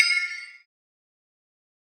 Batmans DarkSoul Perc 12.wav